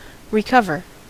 Ääntäminen
IPA : /ɹɪˈkʌvə/